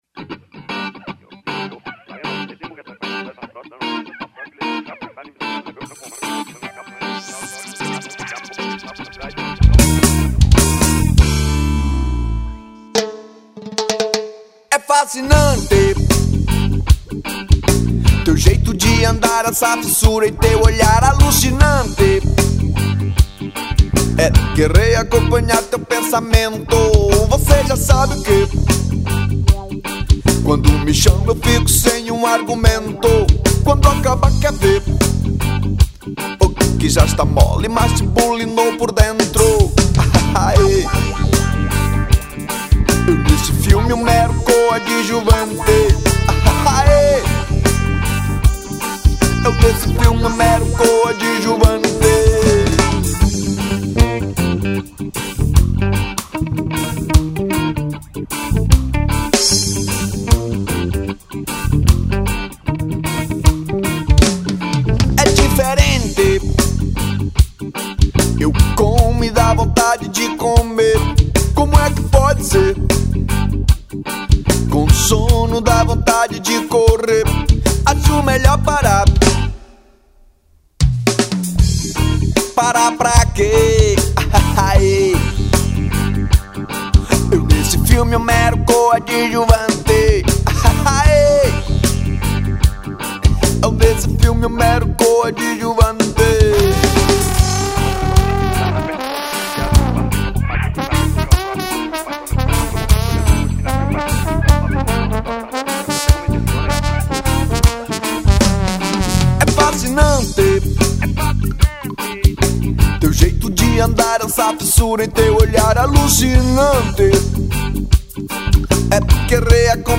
1958   03:41:00   Faixa:     Rock Nacional